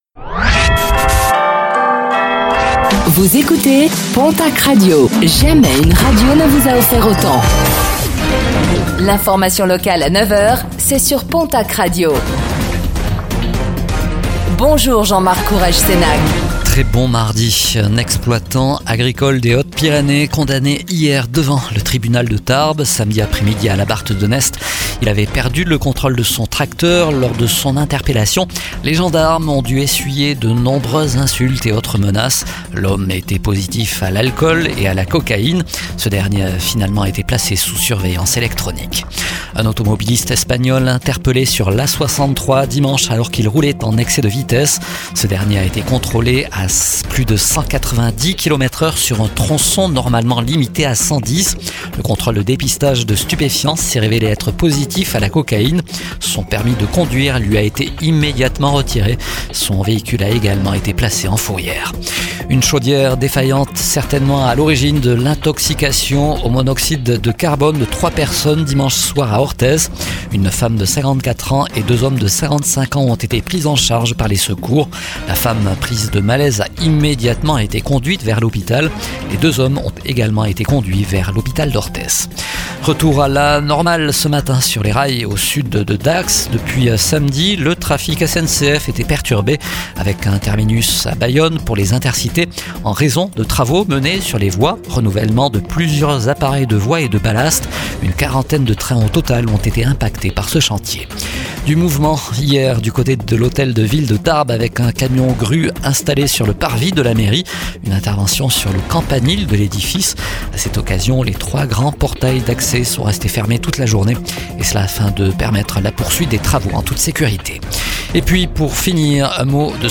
Réécoutez le flash d'information locale de ce mardi 11 novembre 2025